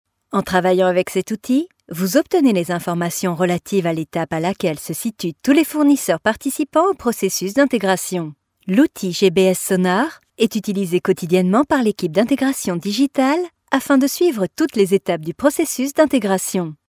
locutora francesa, french voice over